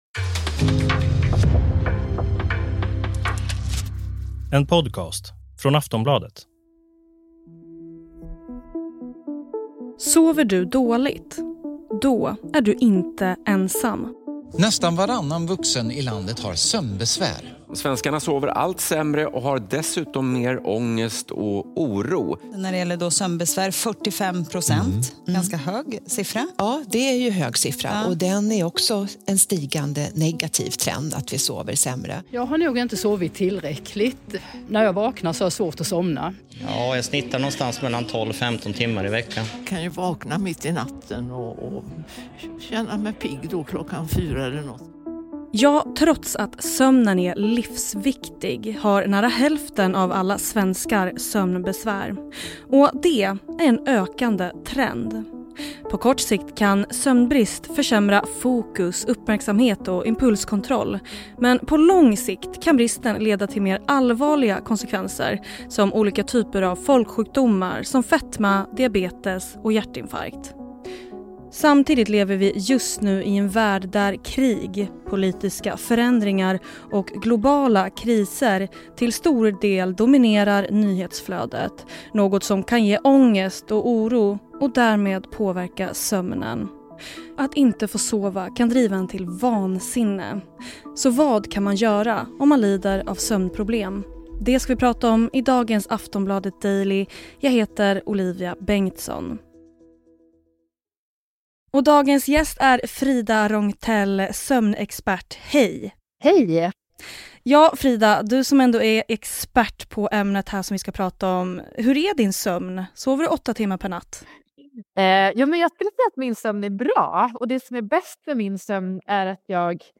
Klipp i avsnittet: TV4 Nyheterna, P4 Skaraborg, P4 Halland, P4 Östergötland.